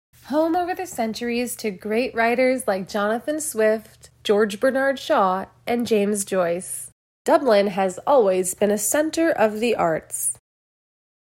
version of the file where I have edited some breaths/pauses.
You can really hear the room tone disappear before the word Dublin and then start again. This is quite jarring.